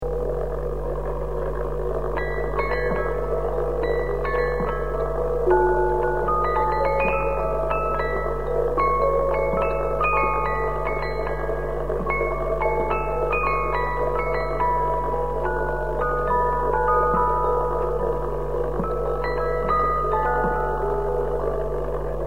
Old Music Box EDELWEISS Records
Needless to say that the quality of these recordings is poor, if measured with nowadays (CD) standards.